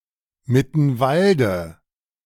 Mittenwalde (German pronunciation: [mɪtn̩ˈvaldə]
De-Mittenwalde.ogg.mp3